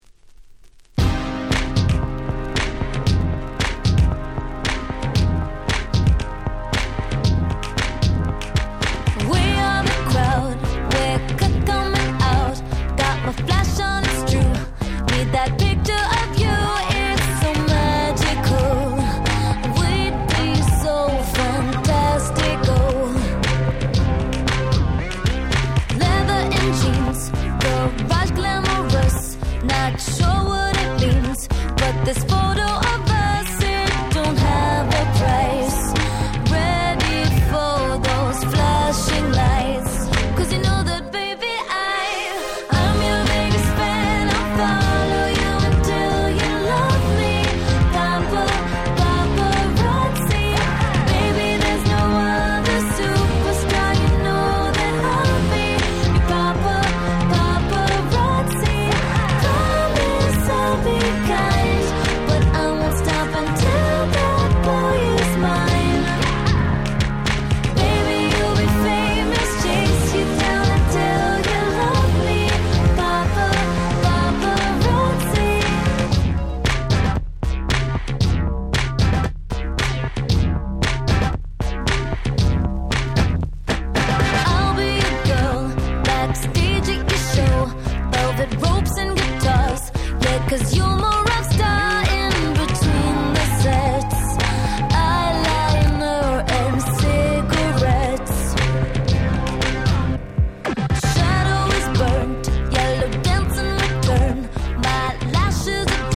09' Super Hit R&B / Pops !!